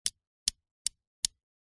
Звуки плоскогубцев
Звук передачи плоскогубцев в руку другому человеку